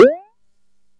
Player Jump.mp3